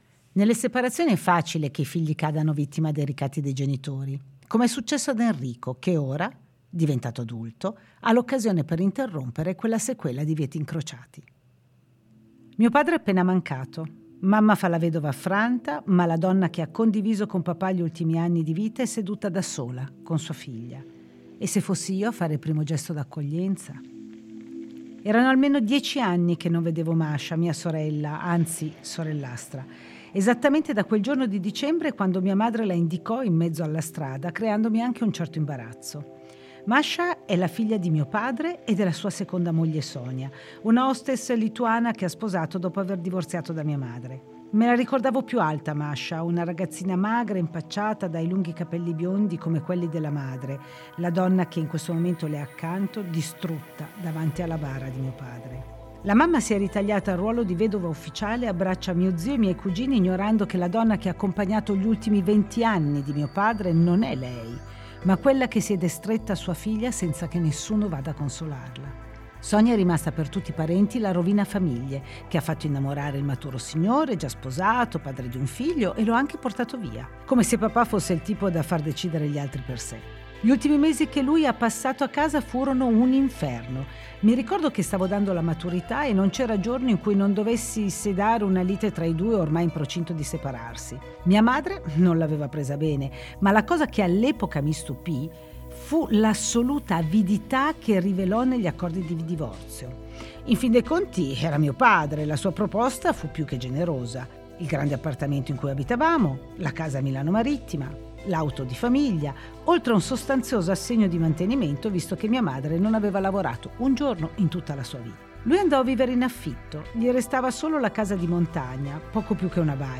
Music under courtesy of Forte Media & Consulting Sagl